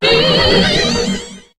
Cri de Magirêve dans Pokémon HOME.